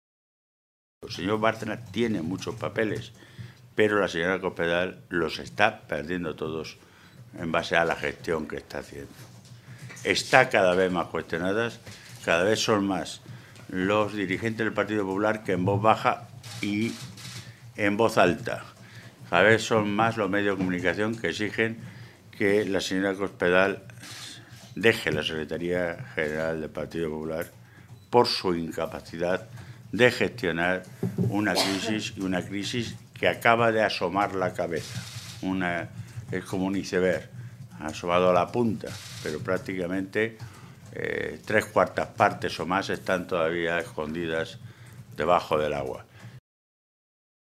Jesús Fernández Vaquero, Secretario de Organización del PSOE de Castilla-La Mancha
Cortes de audio de la rueda de prensa